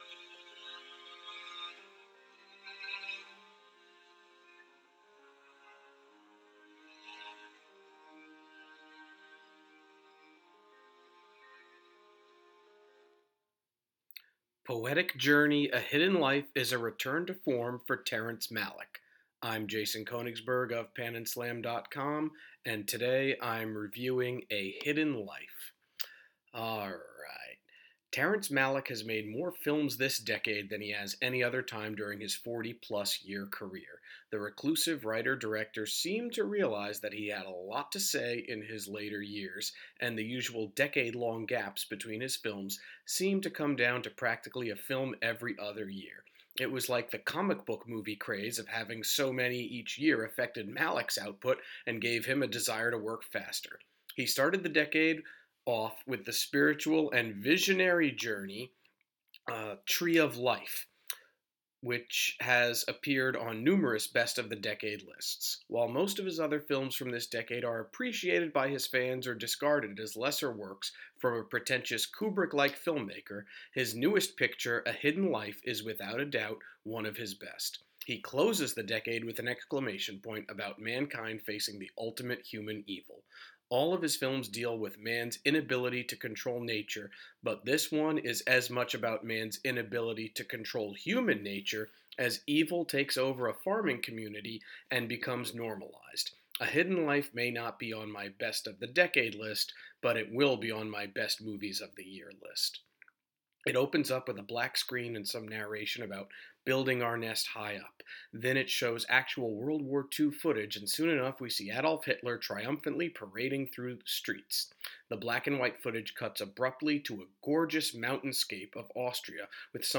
Movie Review: A Hidden Life